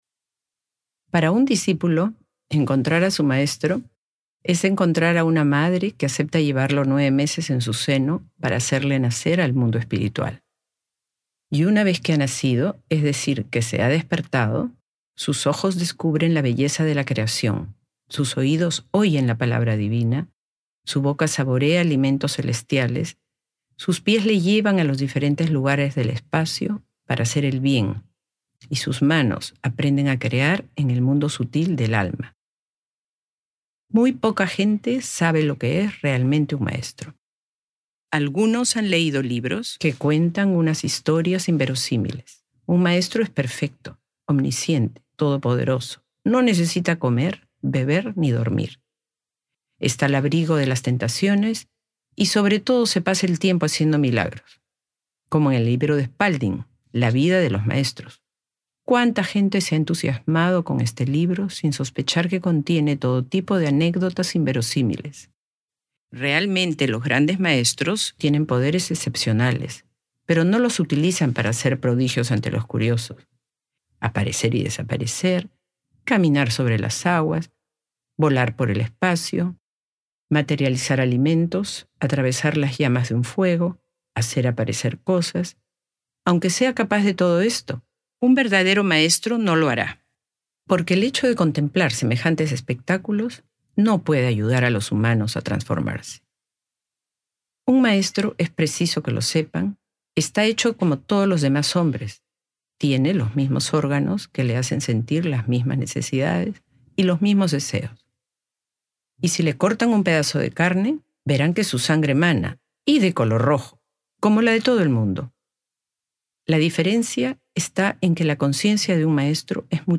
¿Qué es un Maestro espiritual? (Livre audio | CD MP3) | Omraam Mikhaël Aïvanhov